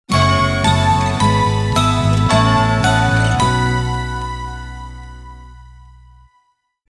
Joyeux noël musical et chanté